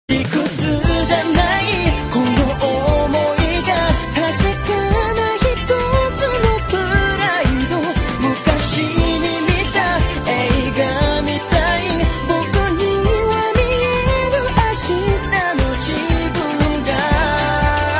• Jazz Ringtones